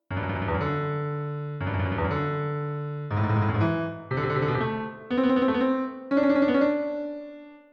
...e as semicorcheas
Cada un dos fragmentos trátaos por separado facendo secuencias.
Trilo_fragmentacion_dobre.mp3